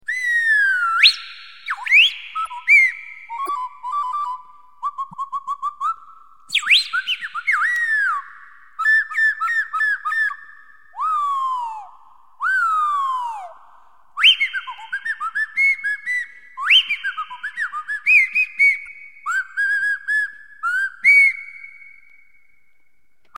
Flûte à nez
Elle permet de reproduire nombre de chants d’oiseaux et de cris d’animaux ainsi que n’importe quelle mélodie.
Acoustiquement la flûte à nez s’apparente à l’ ocarina, la cavité buccale devenant le corps de la flûte.
flute-nez.mp3